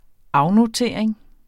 Udtale [ ˈɑwnoˌteˀɐ̯eŋ ]